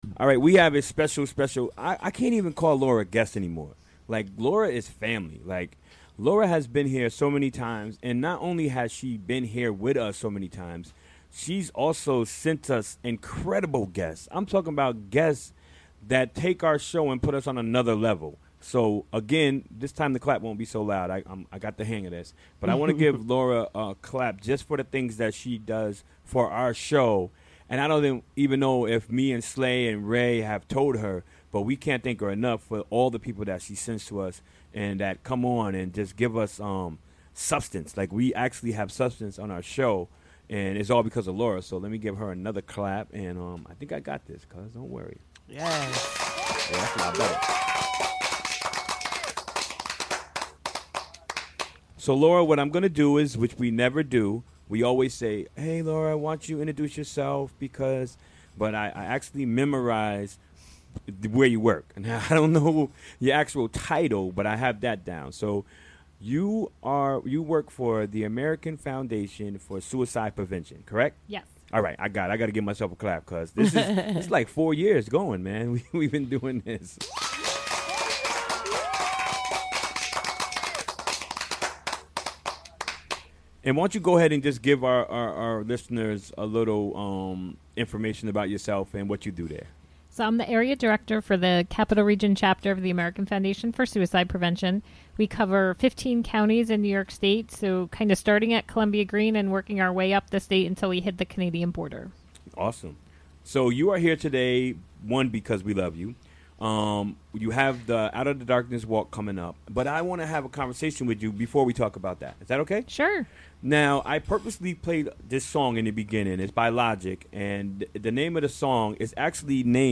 Recorded during the WGXC Afternoon Show on Wednesday, September 6, 2017.